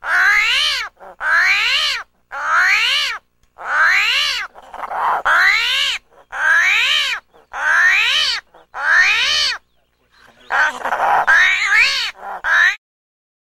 Звук кричащего броненосца armadillo